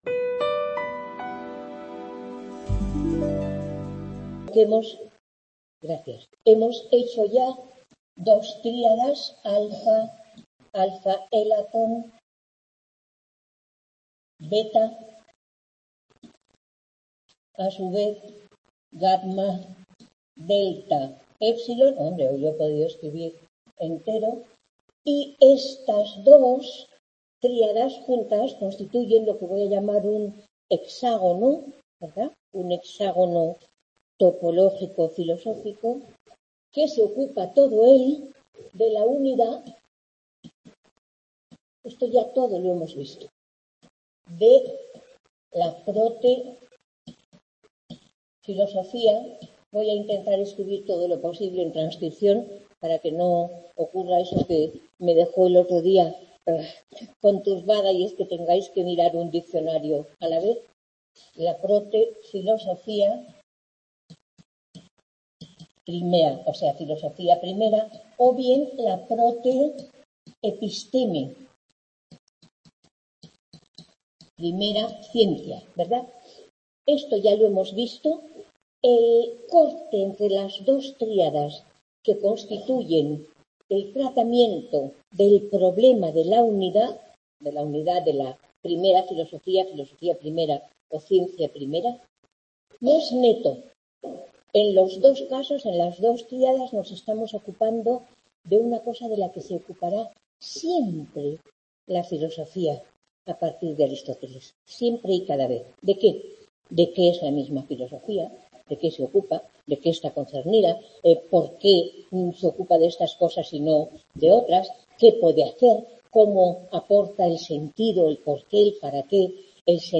Aristoteles Clase 4
Room Madrid-Campus Madrid - Historia De La Filosofía